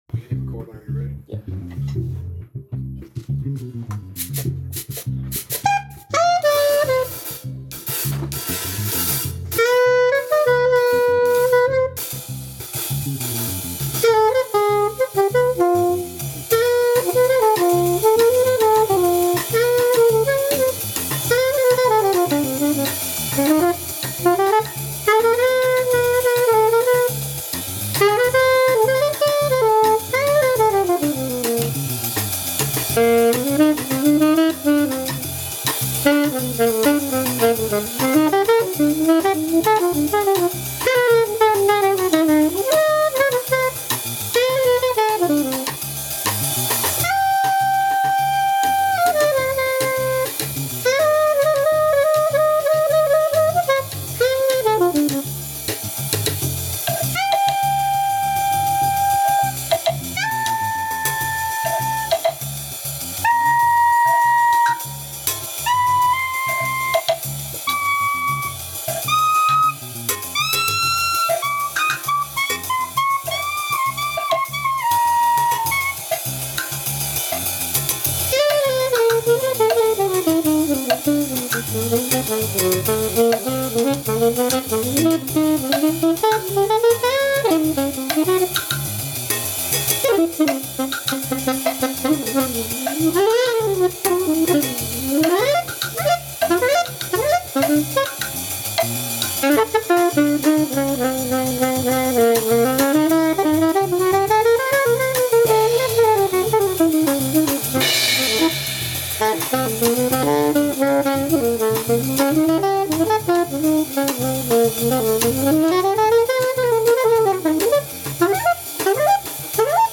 Legislation - Jazz, Band, Pop, Rock - Young Composers Music Forum
The song is played over a vamp, free form. The saxophone chains melismatic ideas across the song.